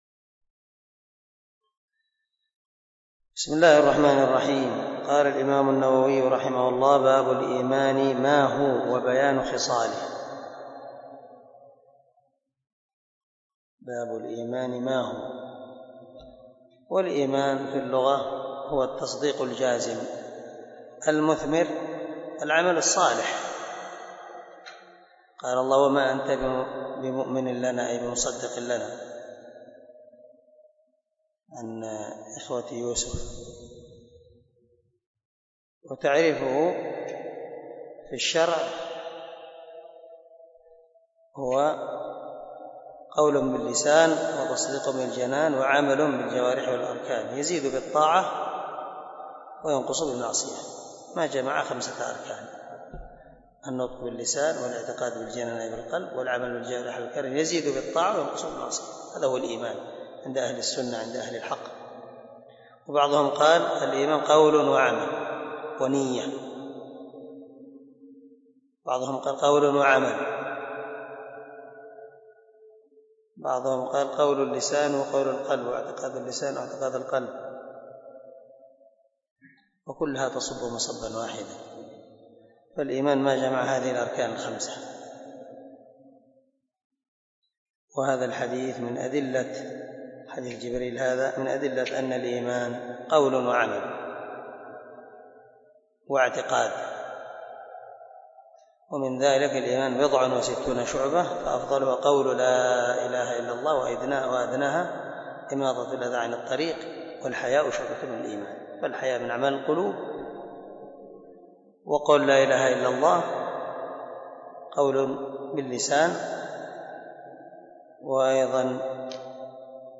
003الدرس 2 من شرح كتاب الإيمان حديث رقم ( 9 ) من صحيح مسلم